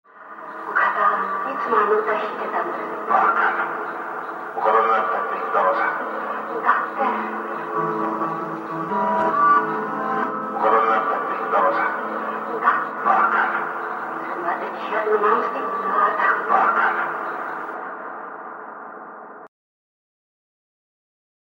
techno trance club